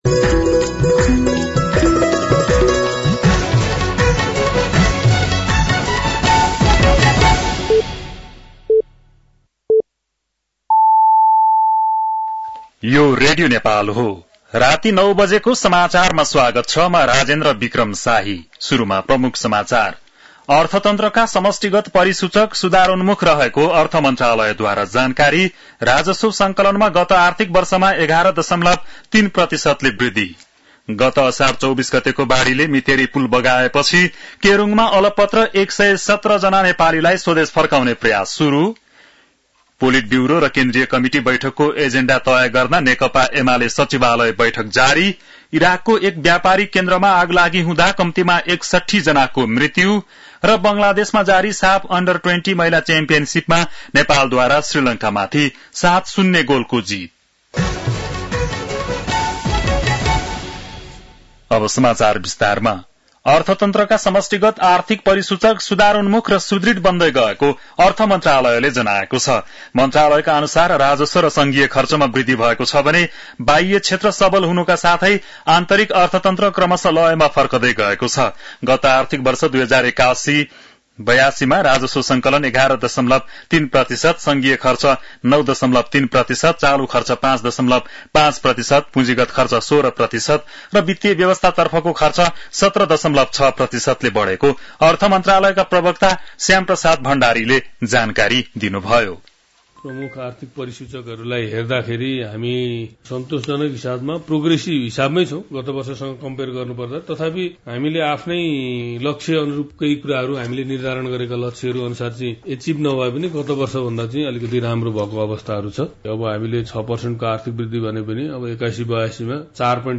बेलुकी ९ बजेको नेपाली समाचार : १ साउन , २०८२
9-PM-Nepali-NEWS-4-1.mp3